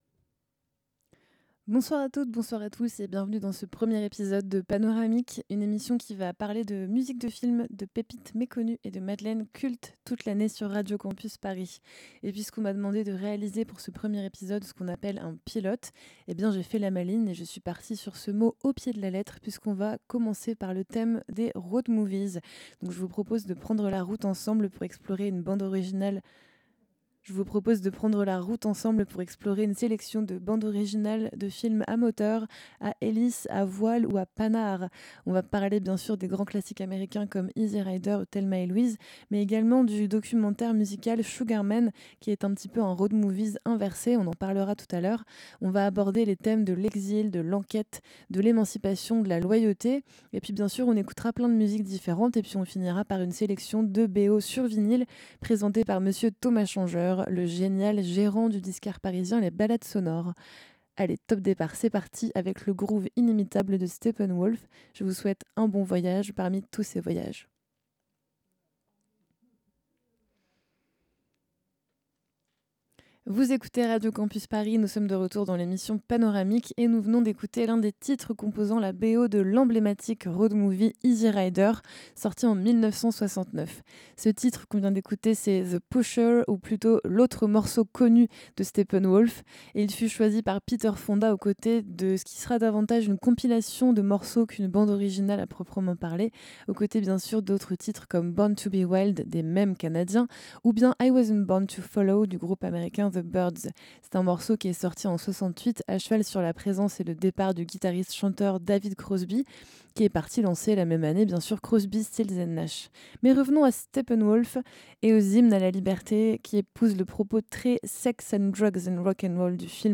Type Mix Courants Alternatifs Éclectique